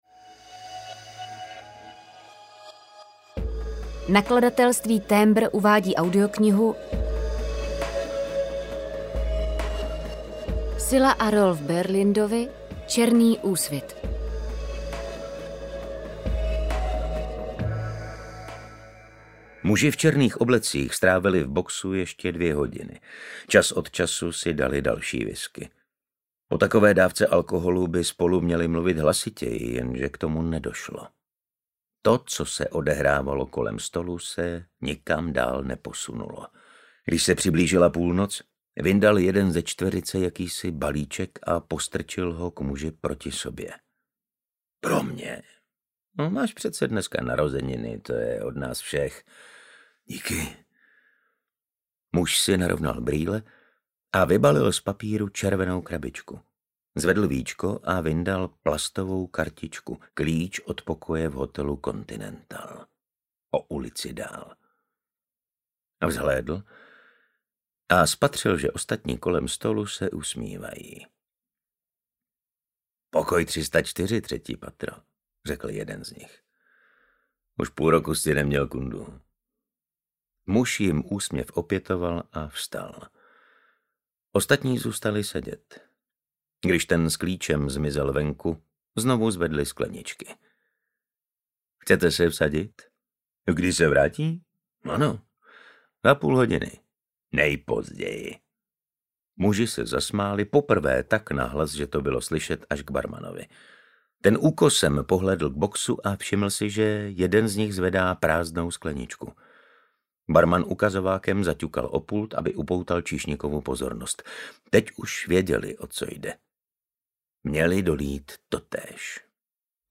Černý úsvit audiokniha
Ukázka z knihy
• InterpretMartin Stránský, Anita Krausová